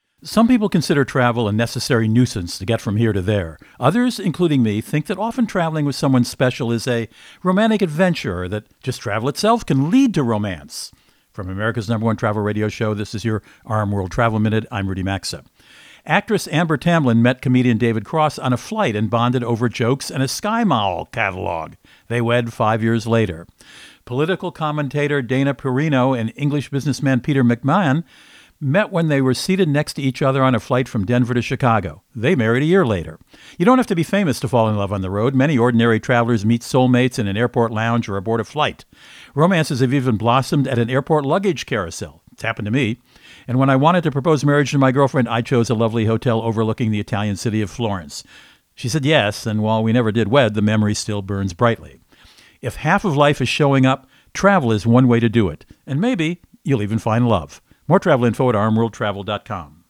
Co-Host Rudy Maxa | Travel, Romance and more…